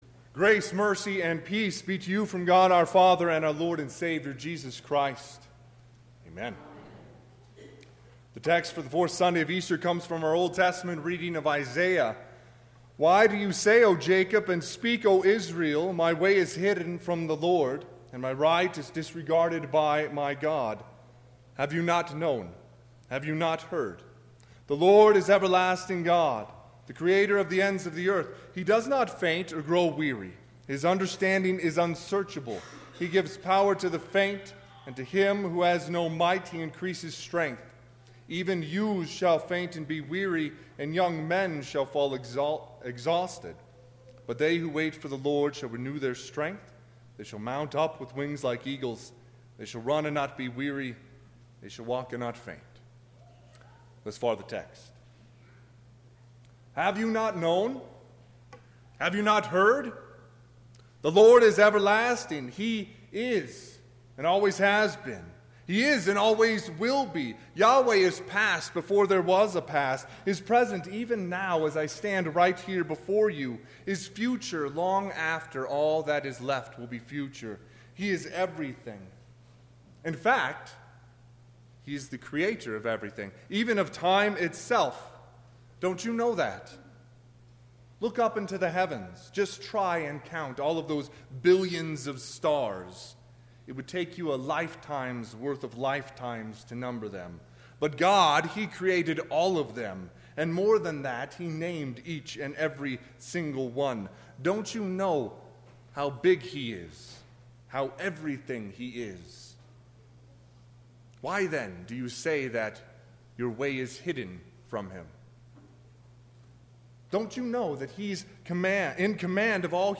Sermon – 5/12/2019